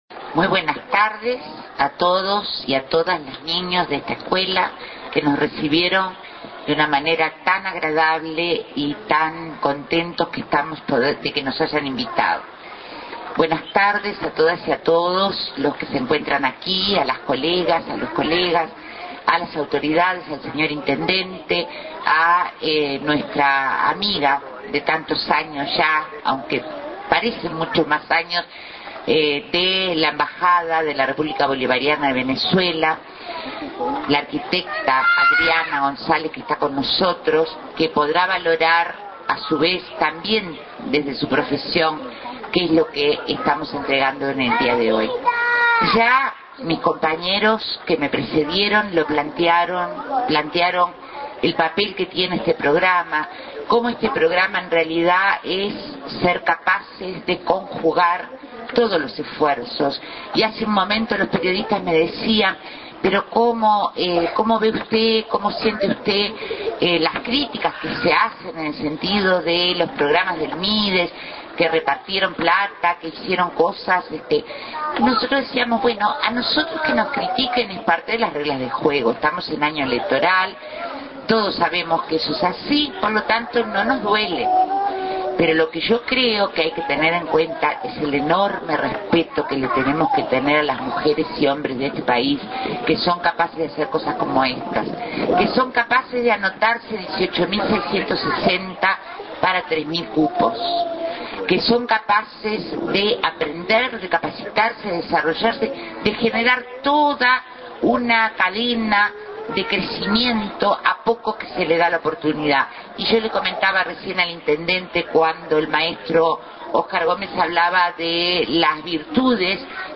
Declaraciones de la Ministra de Desarrollo Social, Marina Arismendi, en la entrega de Aulas en Solís de Mataojo.